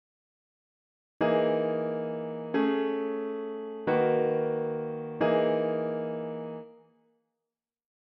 The introduction moves step wise from D# (V?) to G# (I?).